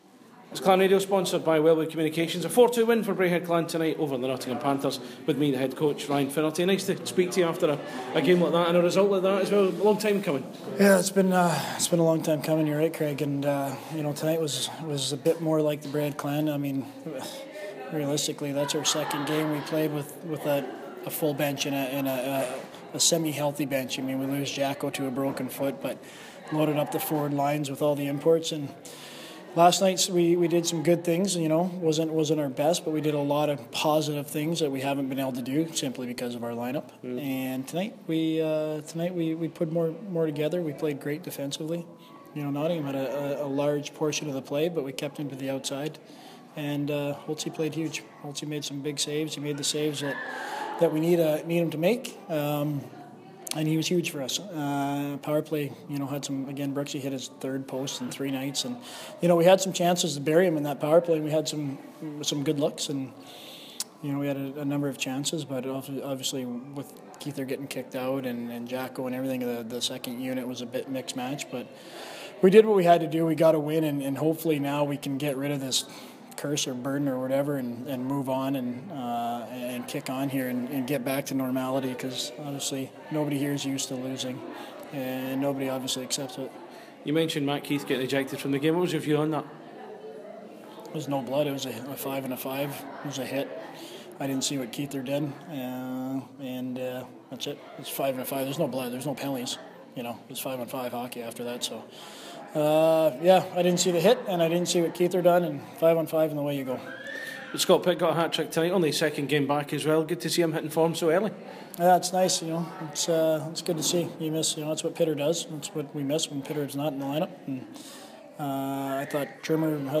He speaks to Clan Radio about the game here.